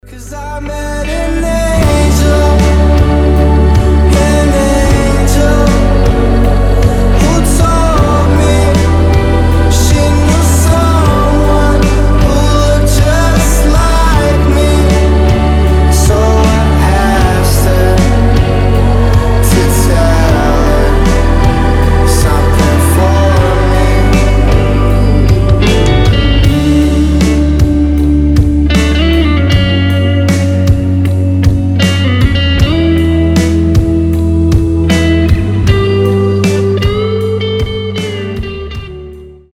• Качество: 320, Stereo
грустные
красивый мужской голос
спокойные
медленные
alternative
печальные